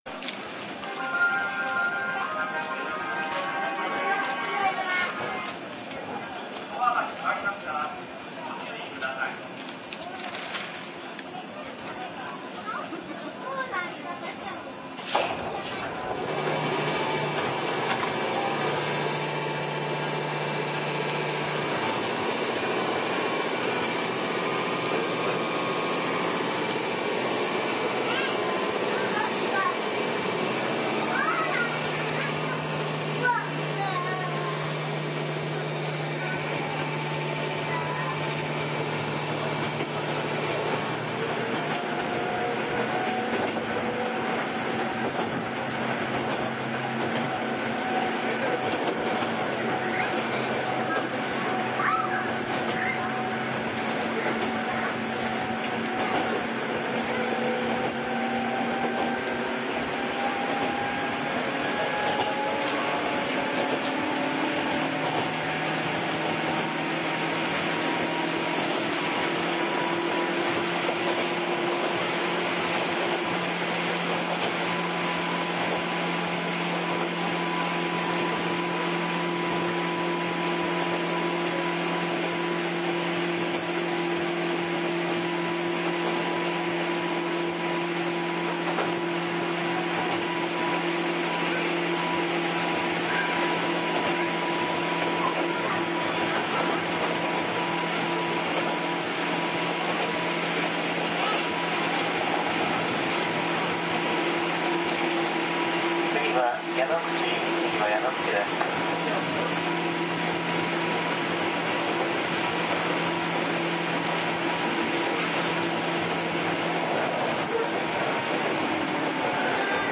特別に(?)、いじめのような爆音コンプレッサーと一緒にどうぞ（でも走行中だと目立ちませんね）。